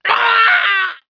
1 channel
loselimb2.wav